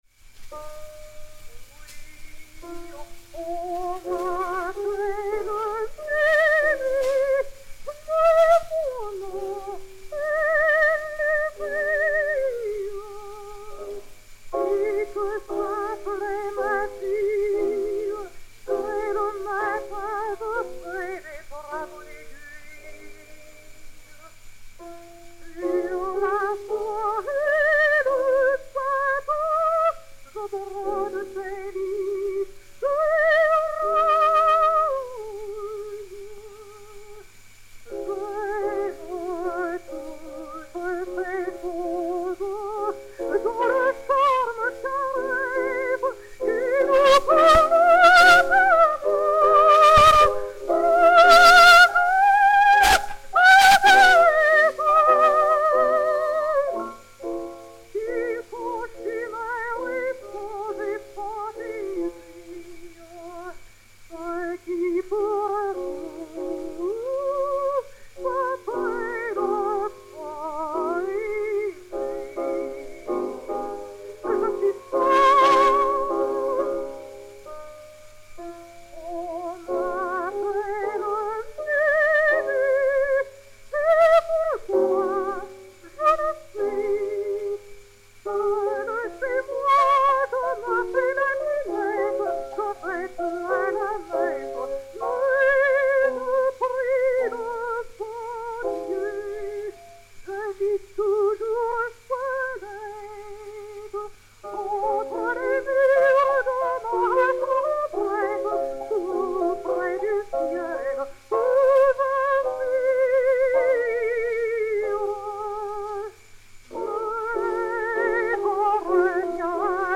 et Piano
Aérophone 1493, enr. vers 1911